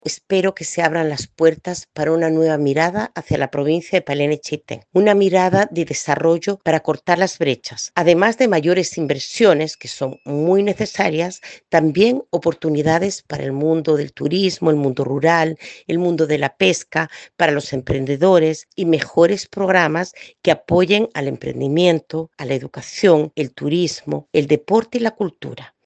A su vez, la alcaldesa electa de Chaitén, Clara Lazcano, sostuvo que es una oportunidad para darle una nueva mirada de inversión a la provincia de Palena.